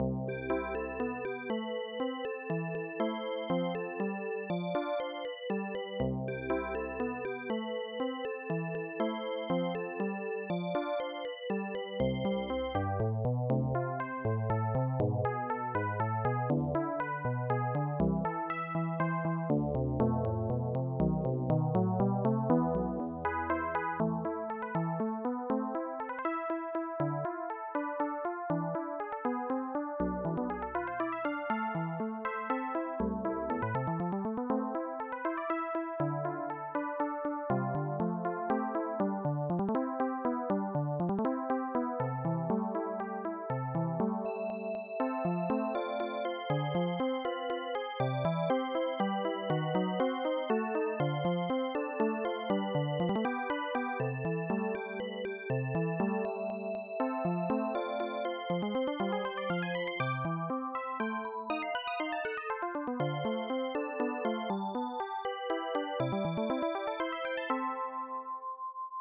/ sound_sensations.iso / rol / poemrol / poem.rol ( .mp3 ) < prev next > AdLib/Roland Song | 1991-03-12 | 3KB | 2 channels | 44,100 sample rate | 1 minute, 9 seconds